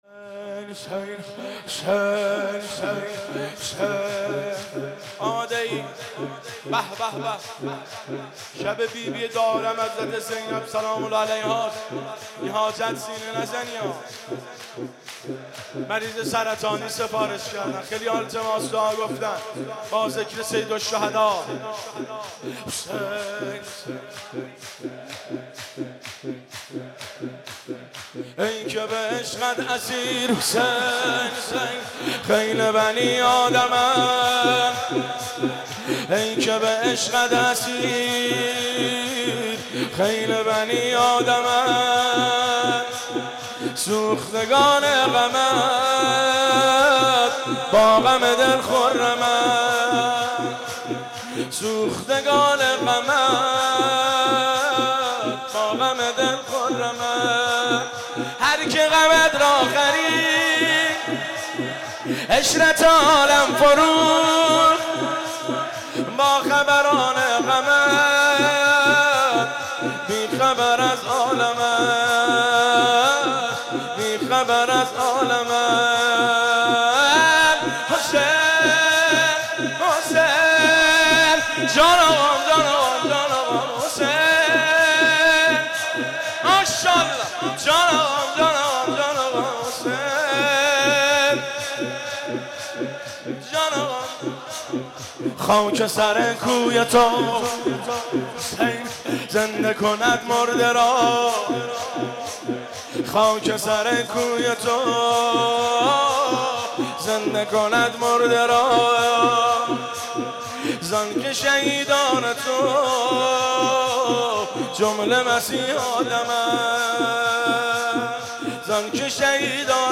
شهادت حضرت زینب(س)/هیات بین الحرمین